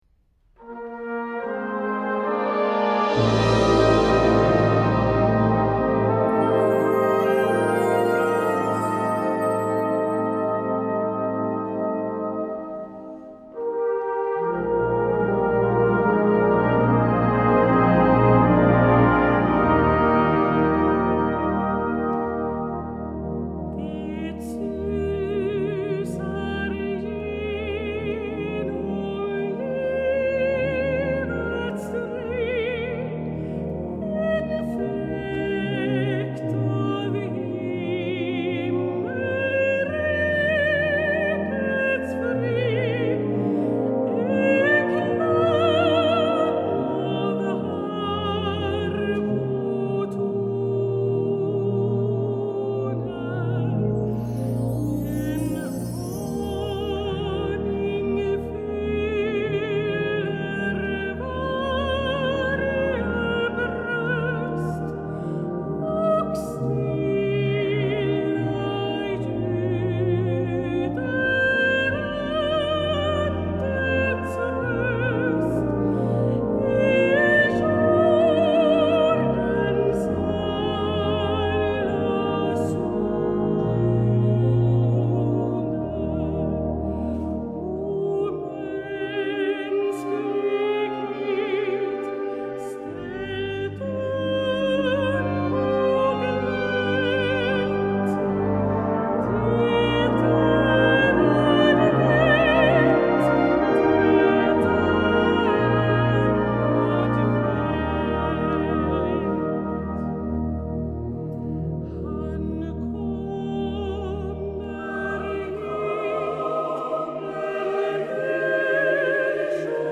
Sopran,SATB, brassband, slagverk, orgel